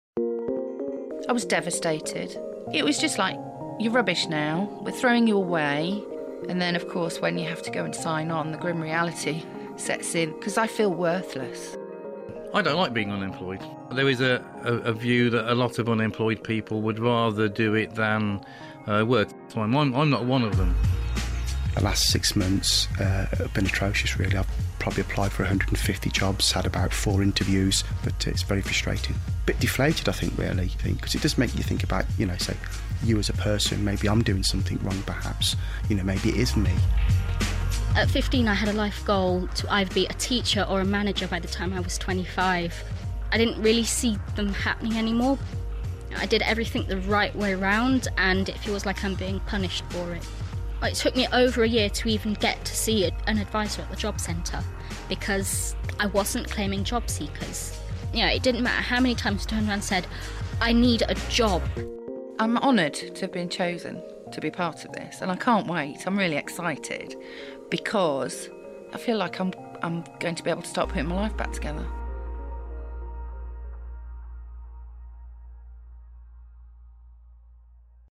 What is it like to be unemployed? Listen to some of our six talking about how they feel.